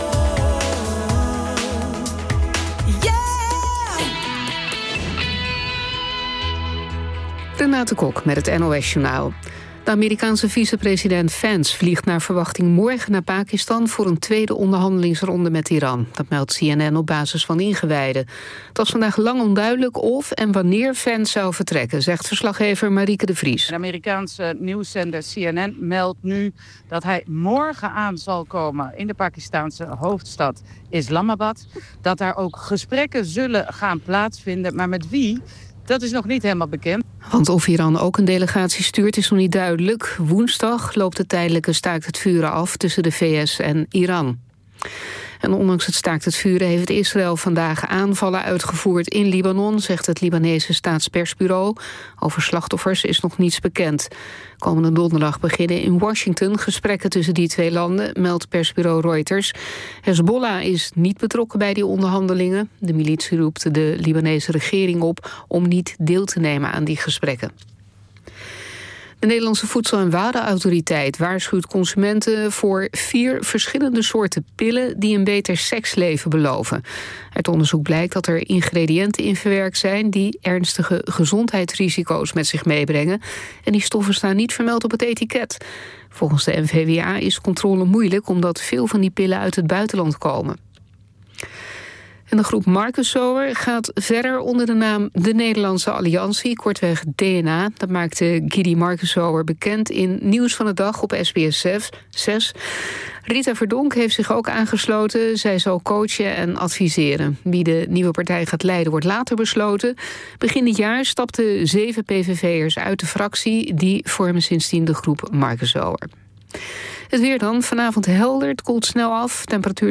Het word vanavond de laatste studio live-uitzending van het seizoen.
Er wordt jazz gedraaid vanaf de jaren 1920 tot en met vandaag. Het zwaartepunt ligt rond de jaren van de swing, bebop en cooljazz (1930-1960) en fusion (1960-1980), maar ook hedendaagse jazz in alle stijlen komt aan bod; de een meer dan de ander. Er wordt zowel vocale jazz als instrumentale jazz ten gehore gebracht, uit alle windstreken, waarbij de nadruk ligt op de Amerikaanse en Nederlandse jazz.